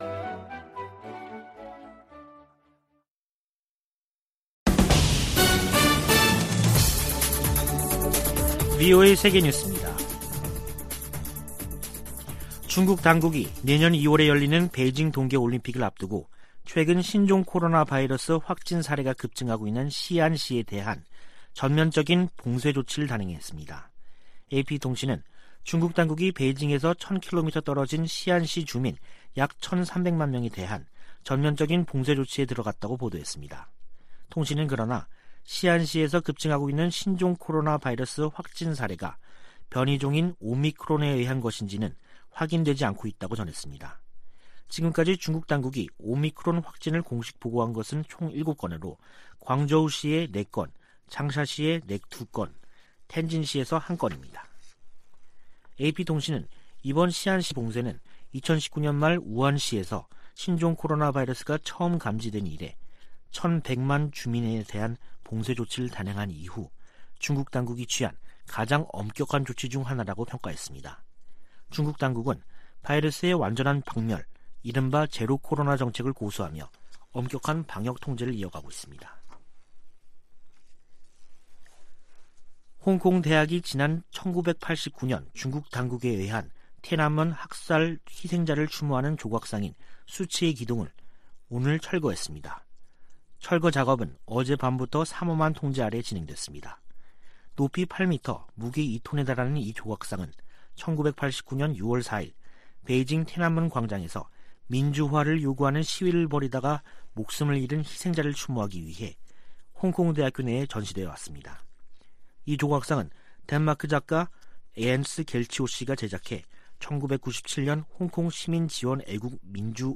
VOA 한국어 간판 뉴스 프로그램 '뉴스 투데이', 2021년 12월 23일 2부 방송입니다. 미 의회가 올해 처리한 한반도 외교안보 관련안건은 단 한 건이며, 나머지는 내년으로 이월될 예정입니다.